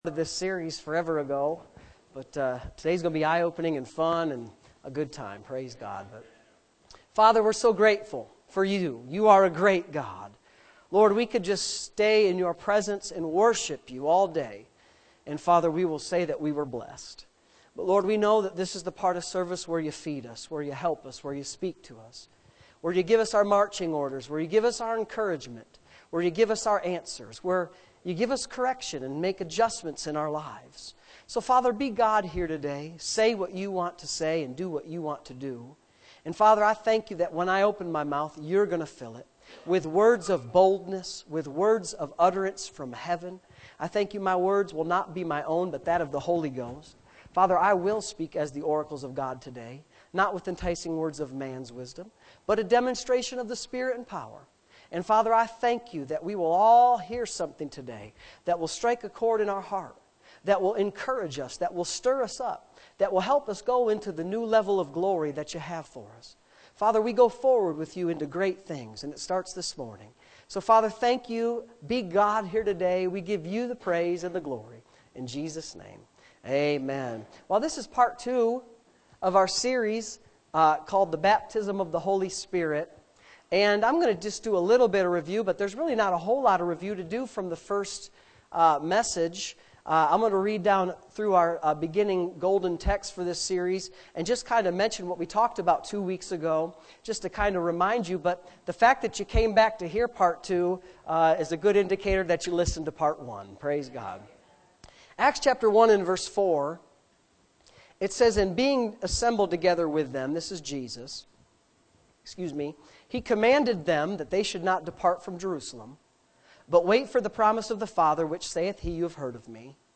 Sunday Morning Services